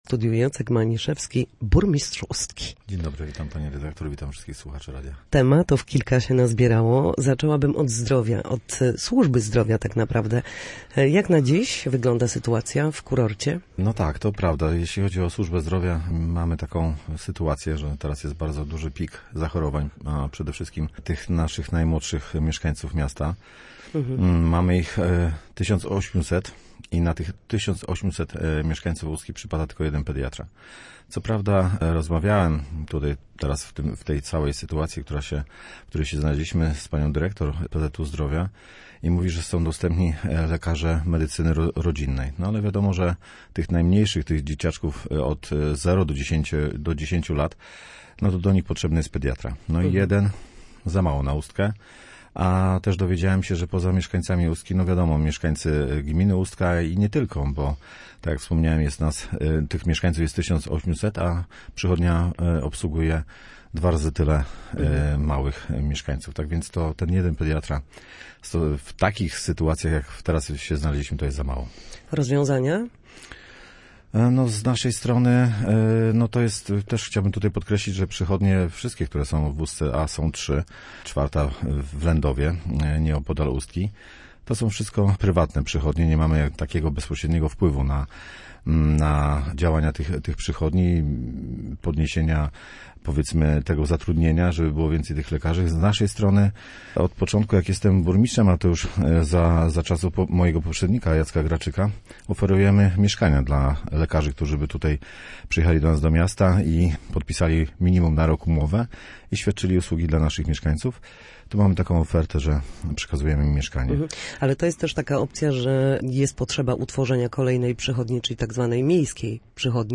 Gościem Studia Słupsk był Jacek Maniszewski, burmistrz Ustki. Na naszej antenie mówił o ochronie zdrowia w kurorcie, dodatkowej karetce, Funduszu Integracyjnym, a także o poszerzeniu granic gminy Ustka kosztem miasta.